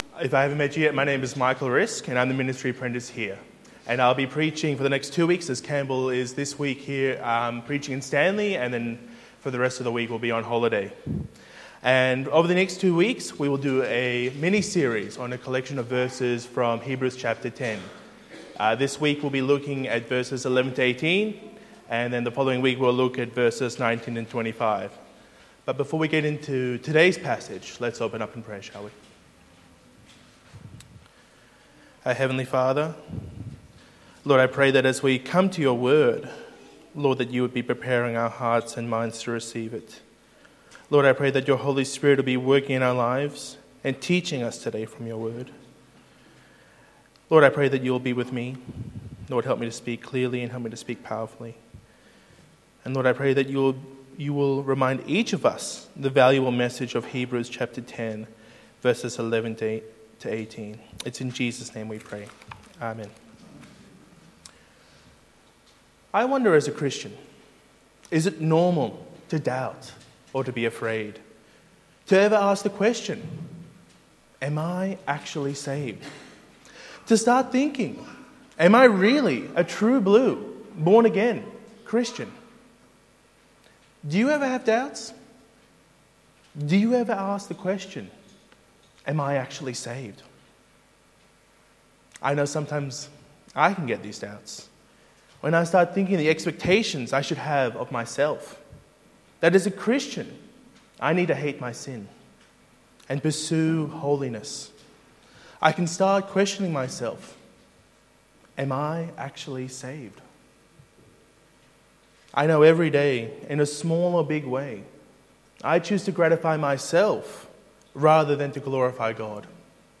Hebrews 10:11-25 Sermon